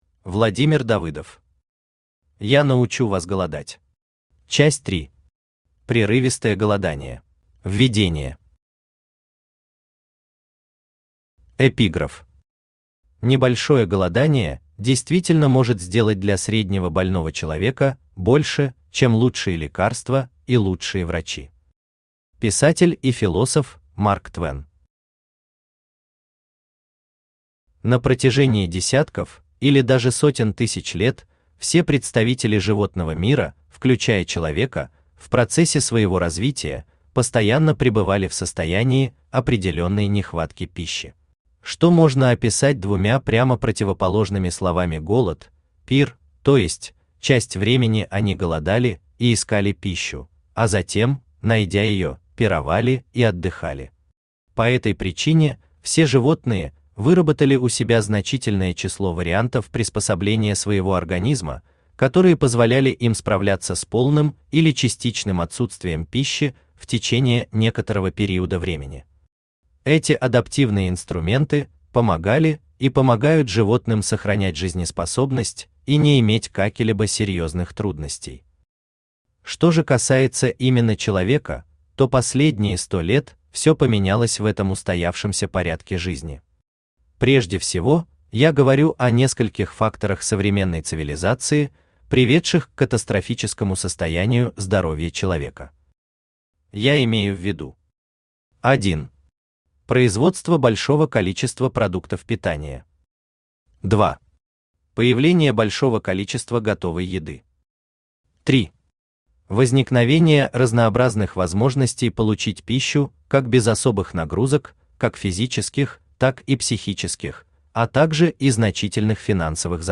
Аудиокнига Я научу вас голодать. Часть 3. Прерывистое голодание | Библиотека аудиокниг
Прерывистое голодание Автор Владимир Давыдов Читает аудиокнигу Авточтец ЛитРес.